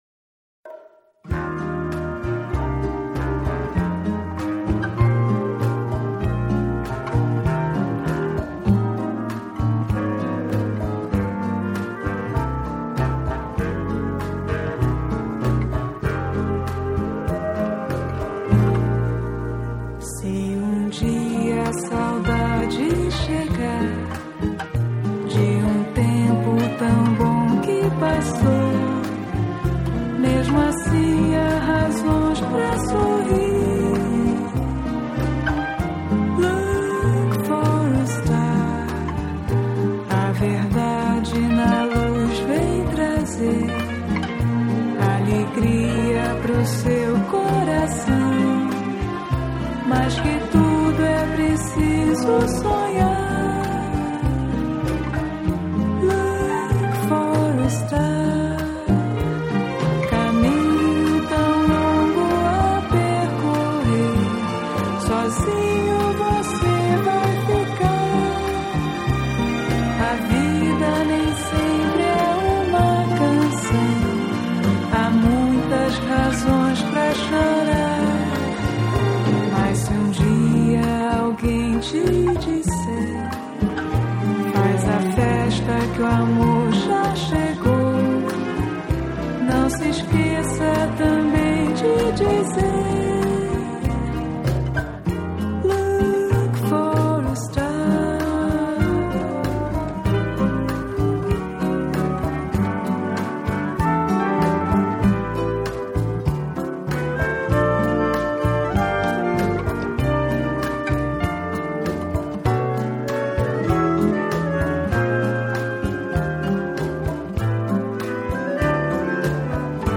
10歳までブラジルで育った 彼女のルーツを活かし、現地の空気感をそのまま封じ込めるためブラジル録音が選ばれたそうです。
僕はボサノヴァはあまり聴かないのですが、全部涼しげな雰囲気